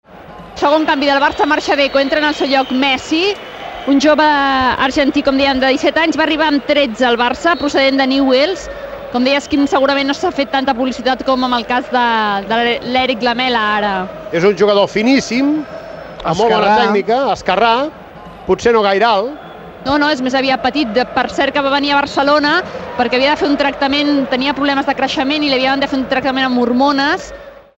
Transmissió del partit de lliga de la primera divisió masculina de futbol entre el R.C.D.Espanyol i el F.C:Barcelona. Canvi a l'equip del Barça. Leo Messi substitueix Deco i debuta en un partit oficial de l'equip
Esportiu